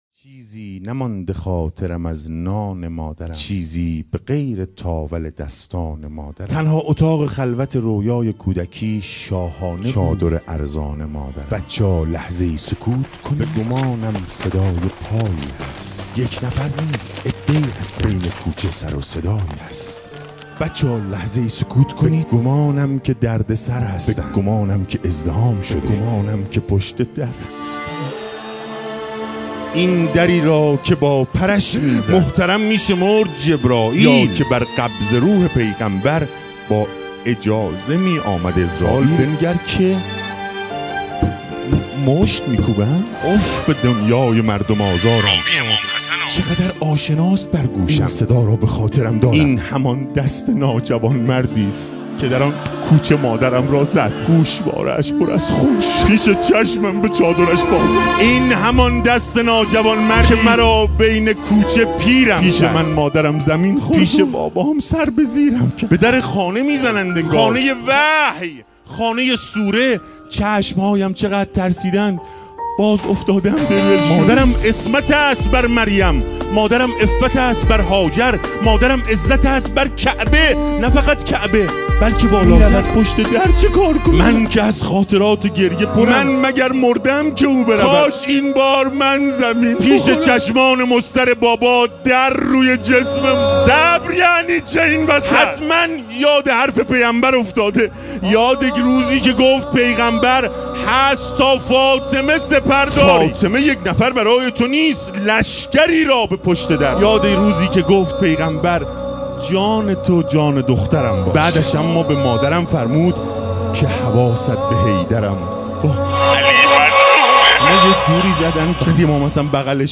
صابر خراسانی آستان مقدس امام زادگان محمدوسکینه خاتون 7شهریور 98